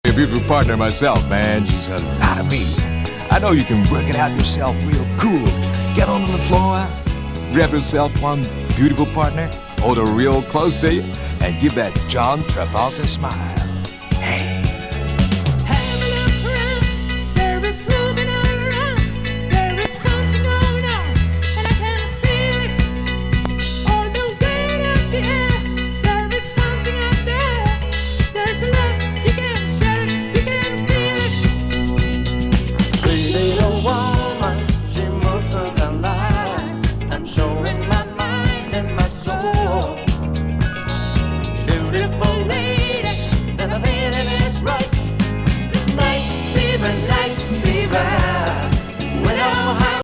ディスコ・ヒット・カバー！